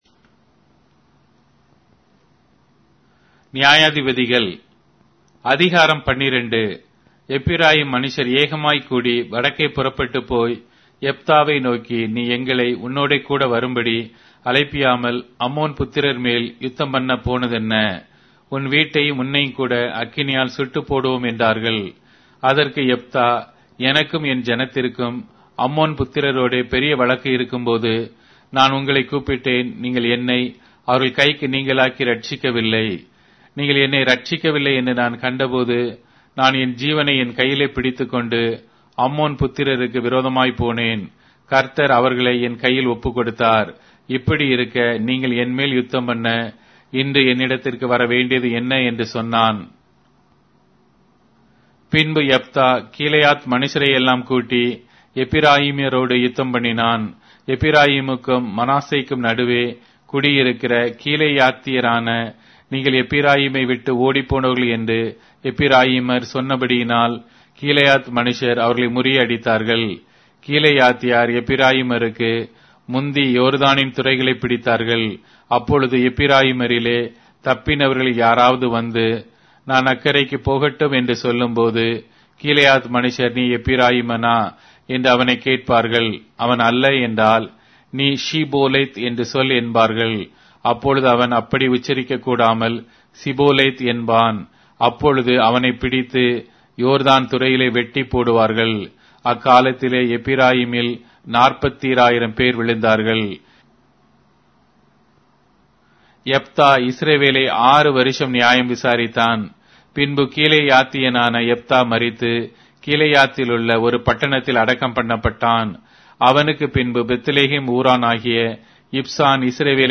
Tamil Audio Bible - Judges 14 in Irvte bible version